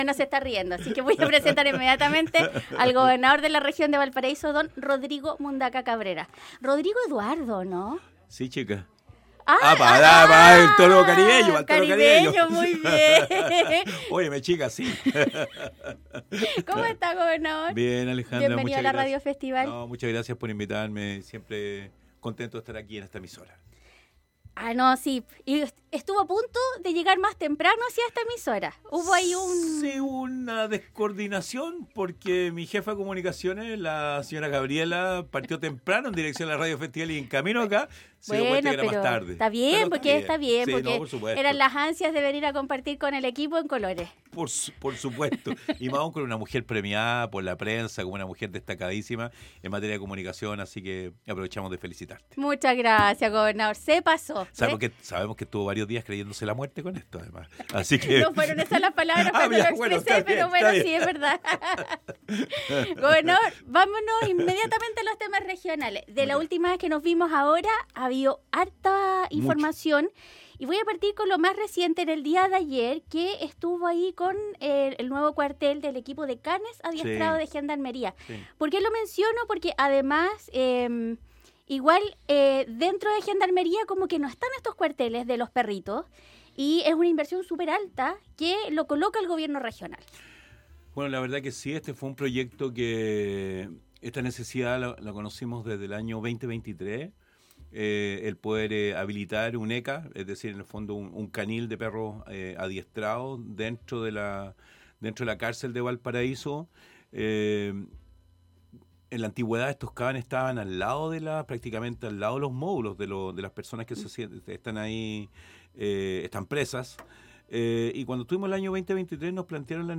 El Gobernador de la región Rodrigo Mundaca Cabrera estuvo en los estudios en Colores para contar detalles de las inversiones del GORE en temas de seguridad en la Región, así como temas de Patrimonio.